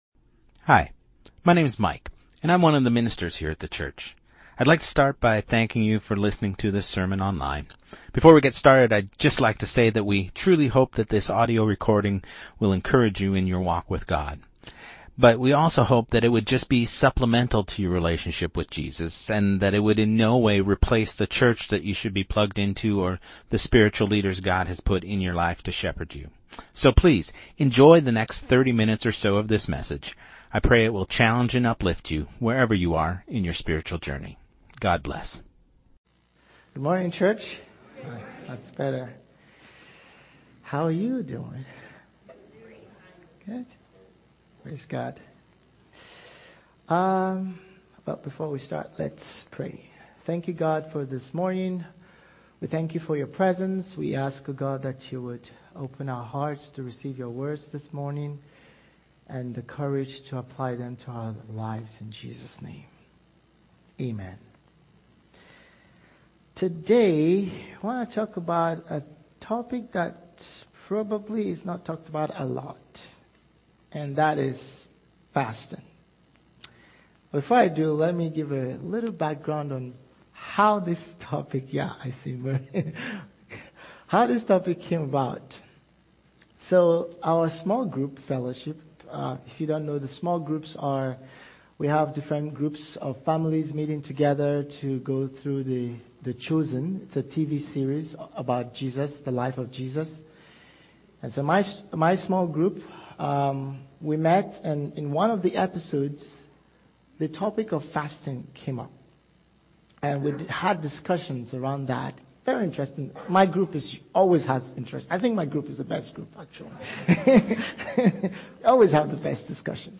Sermon2025-12-28